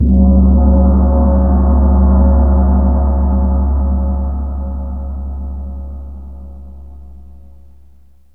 Index of /90_sSampleCDs/Roland L-CD701/PRC_Asian 2/PRC_Gongs
PRC DEEPGONG.wav